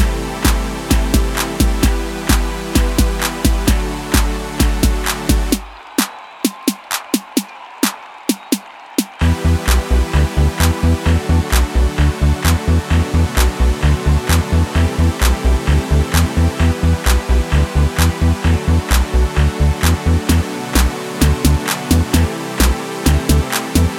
no Backing Vocals at all R'n'B / Hip Hop 4:29 Buy £1.50